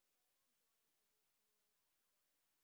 sp30_train_snr30.wav